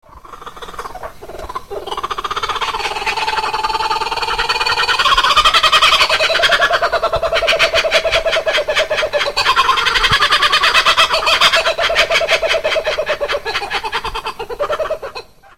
The name is onomatopoeic of its loud, distinctive laughing call, which has been described as sounding like 'fiendish laughter'.
kookaburra.mp3